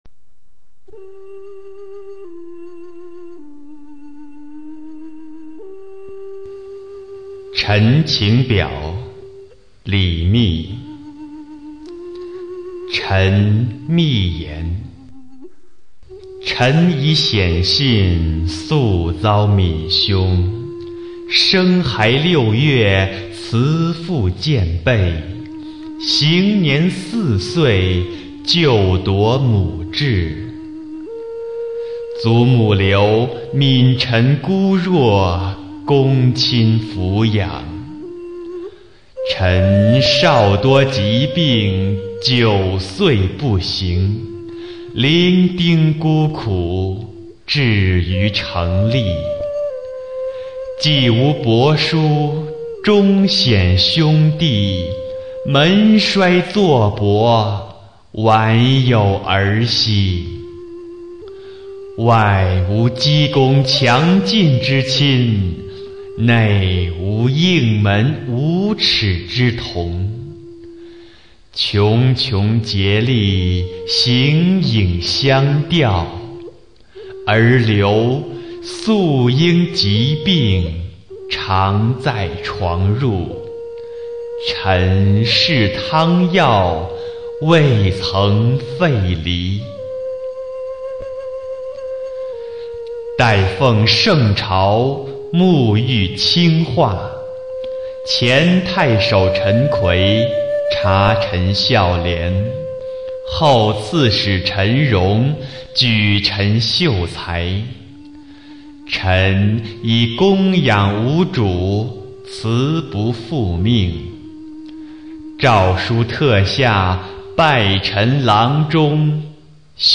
李密《陈情表》原文和译文（含精美朗读）　/ 李密